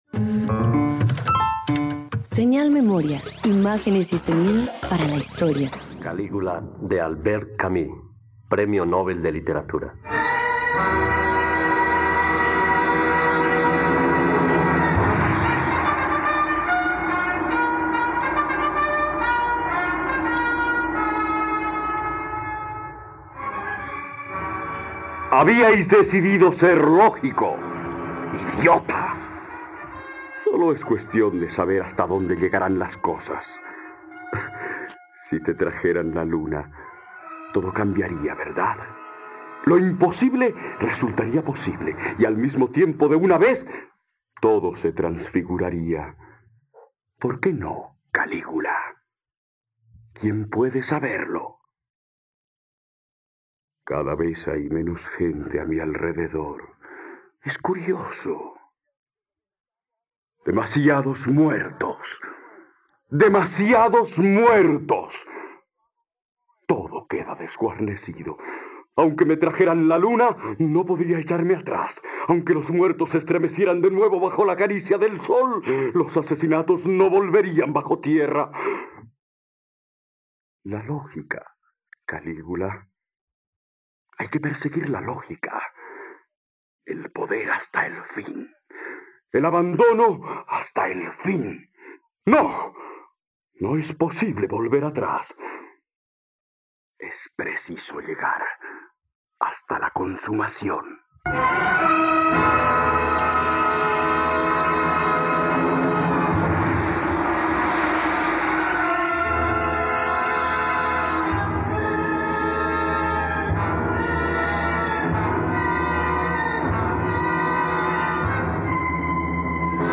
Calígula - Radioteatro dominical | RTVCPlay